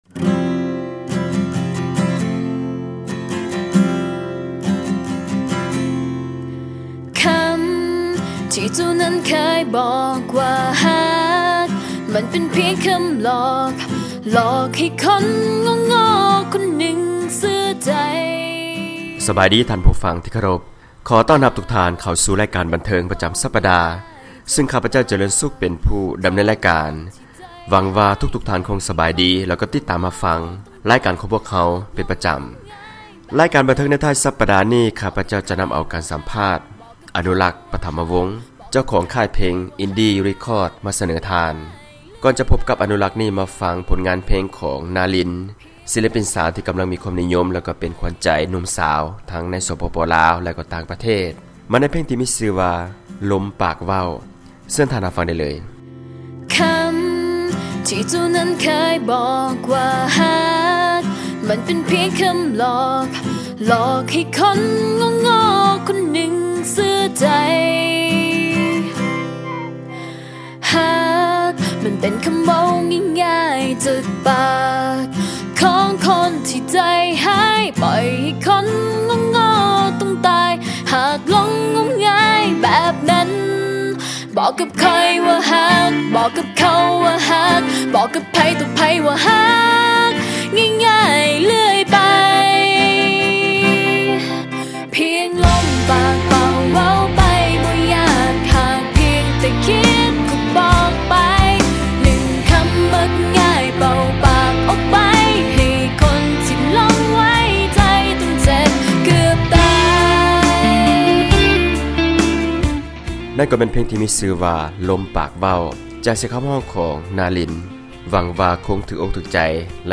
ການສັມພາດ